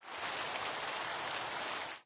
rain7.ogg